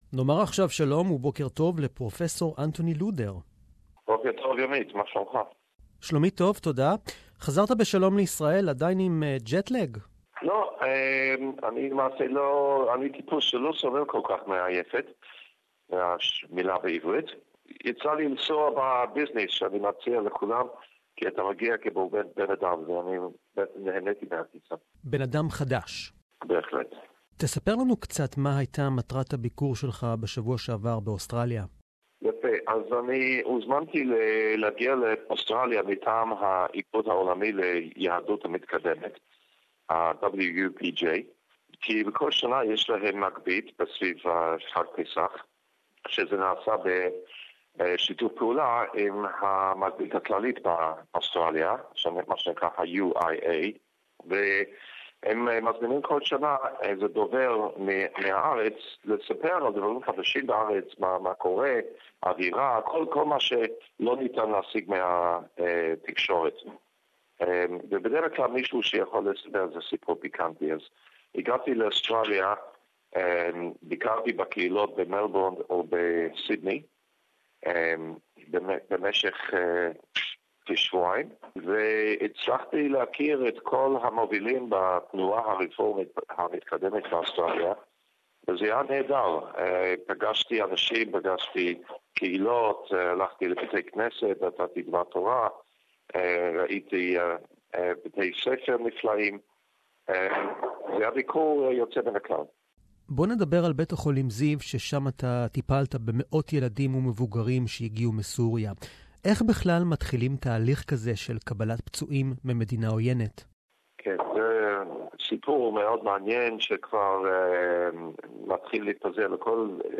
Interview in Hebrew Share